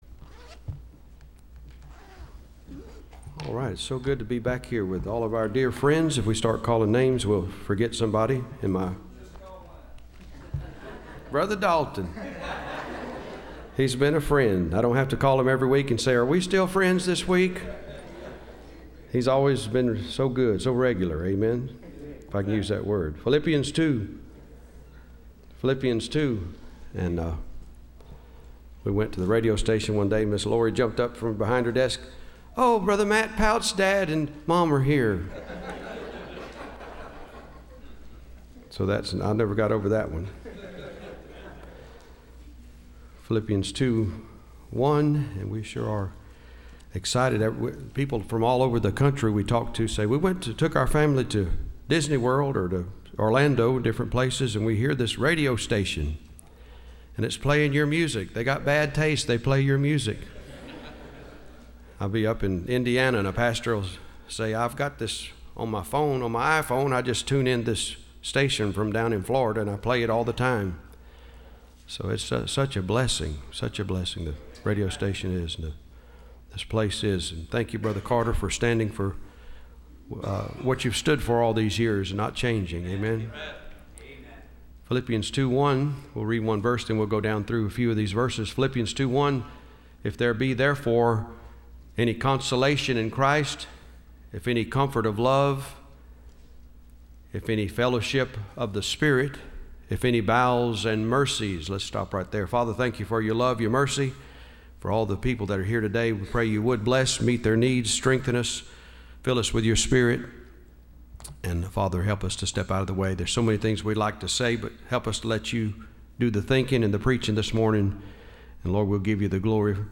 Listen to Message
Service Type: Bible Conference